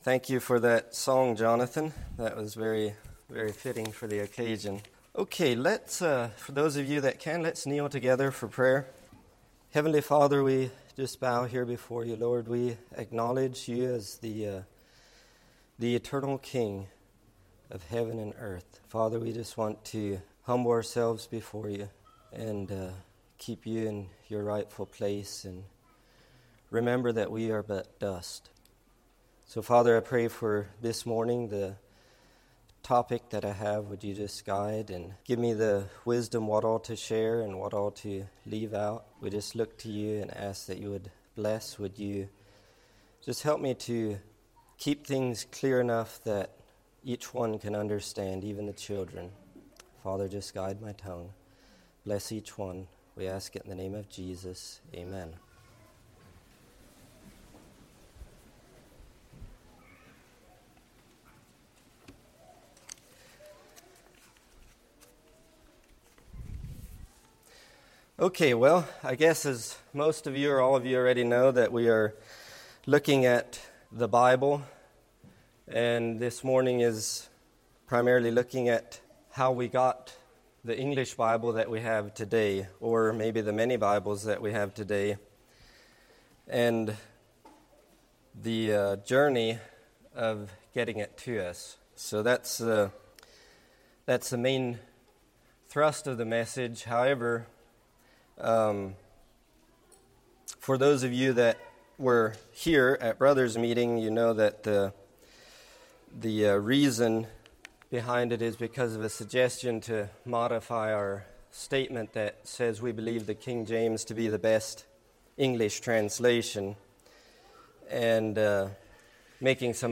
A message from the series "2026 Messages."